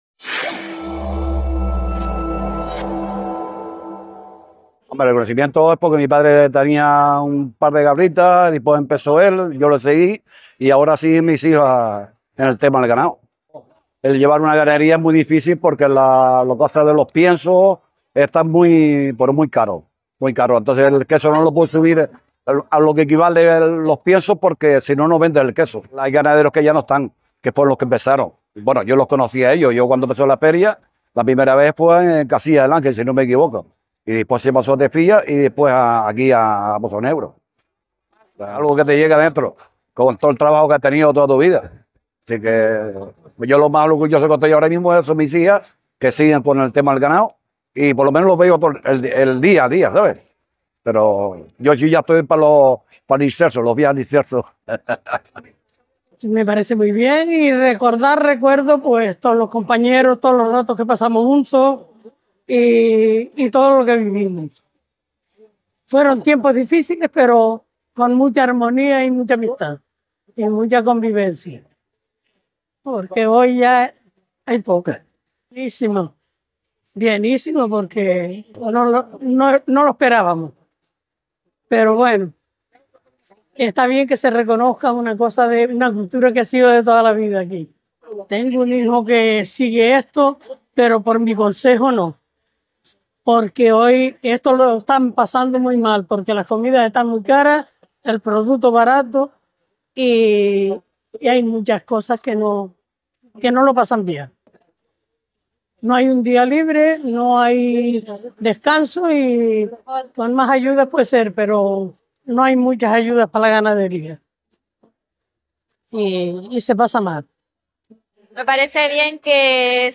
Testimonios de los ganaderos y ganaderas que iniciaron Feaga